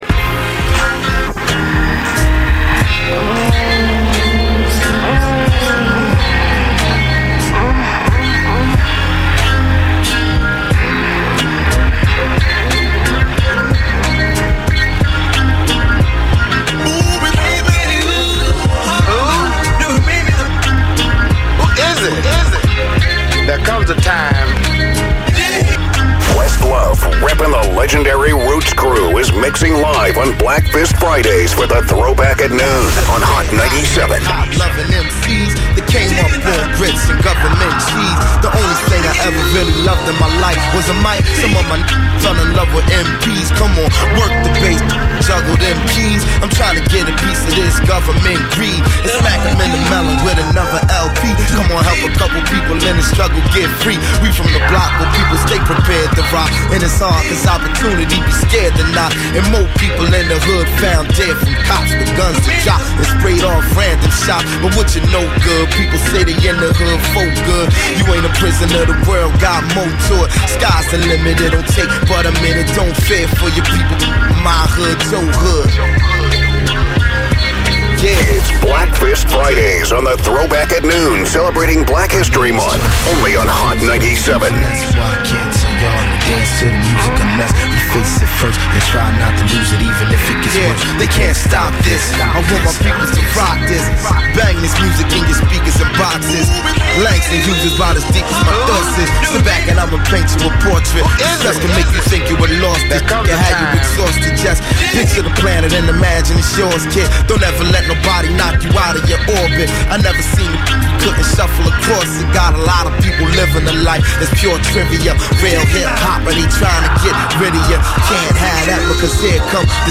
It’s over an hour of head-bobbing madness